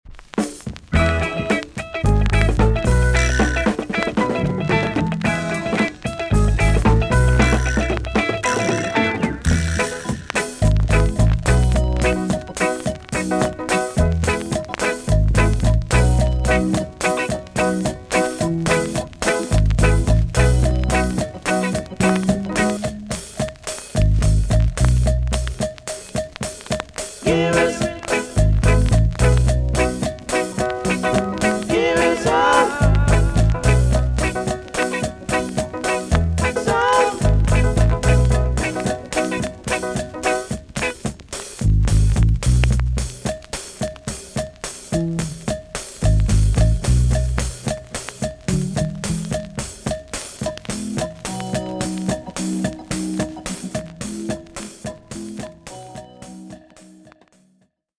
TOP >REGGAE & ROOTS
B.SIDE Version
VG+ 少し軽いチリノイズがありますが良好です。